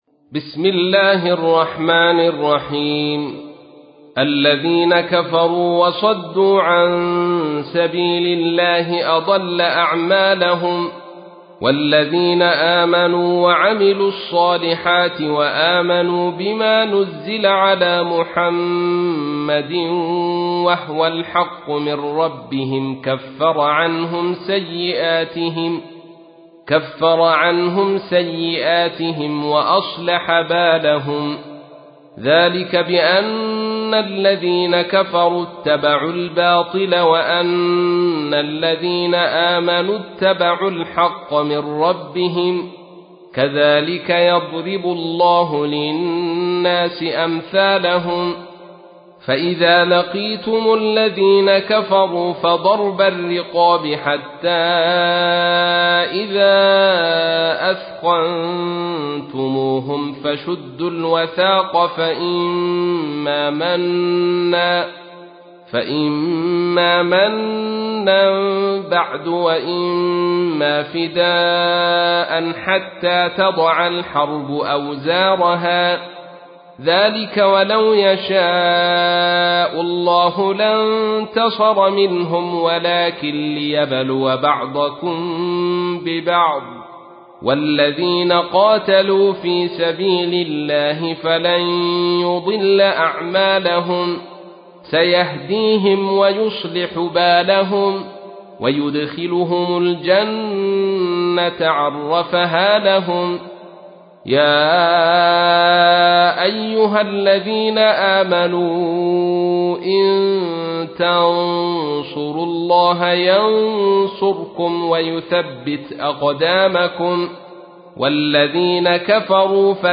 تحميل : 47. سورة محمد / القارئ عبد الرشيد صوفي / القرآن الكريم / موقع يا حسين